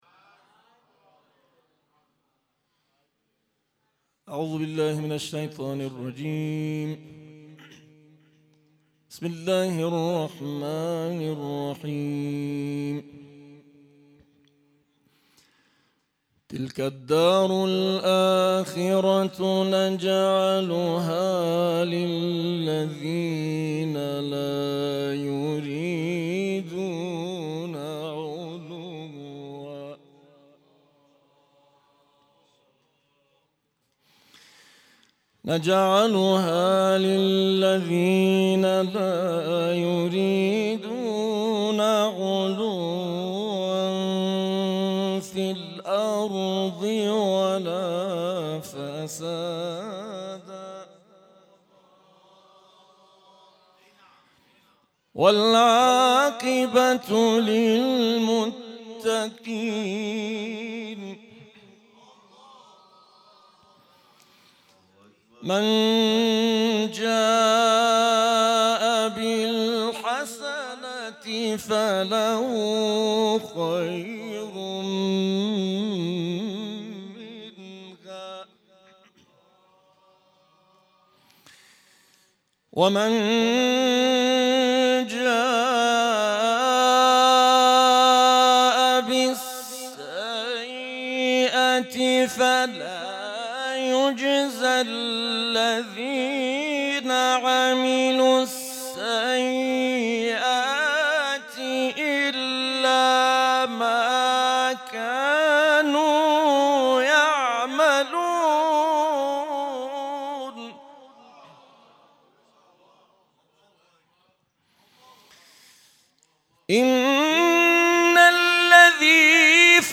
بیست و هفتمین محفل از سلسله محافل منادی با حضور قاریان، حافظان، استادان و پیشکسوتان قرآنی کشور به یاد شهدای مظلوم غزّه برگزار شد.
تلاوت
در محفل منادی بیست‌و‌هفتم